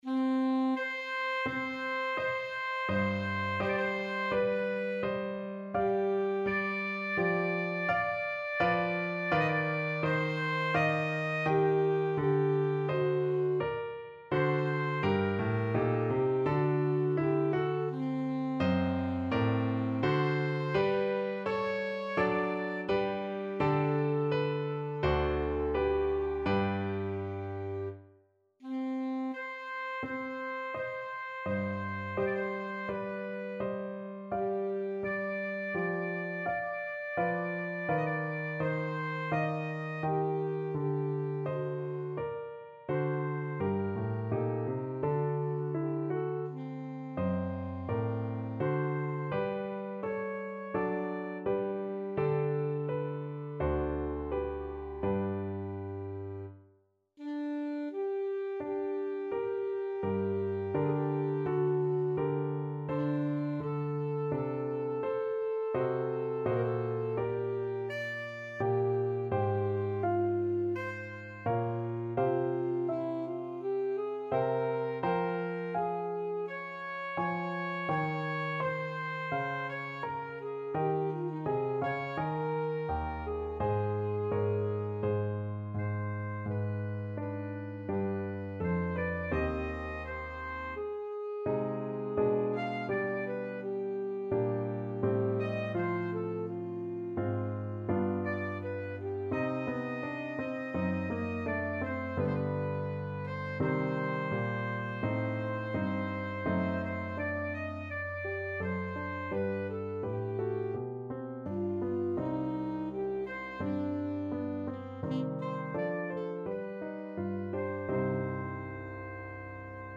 Classical Vivaldi, Antonio Sonata No. 5 in E Minor, Op. 14, First Movement Alto Saxophone version
Alto Saxophone
4/4 (View more 4/4 Music)
Largo =42
Classical (View more Classical Saxophone Music)
vivaldi_sonata_em_op14_5_1_ASAX.mp3